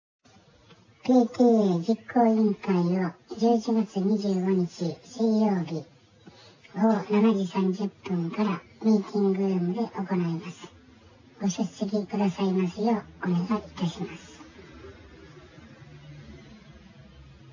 ➤PTA実行委員会（ボイス）